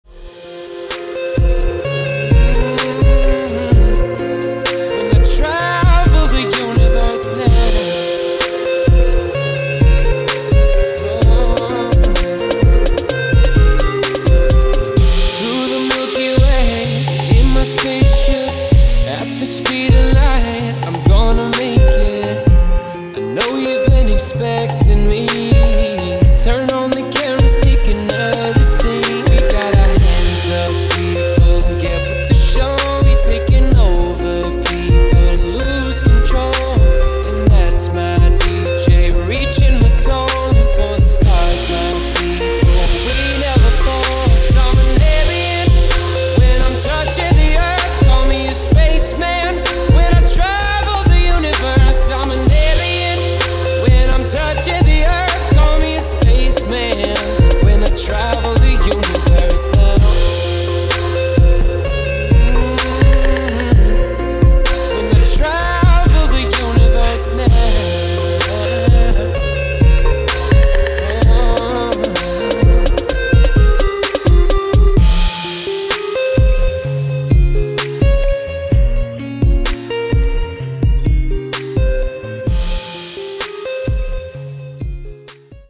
• Качество: 320, Stereo
спокойные
спокойный рингтон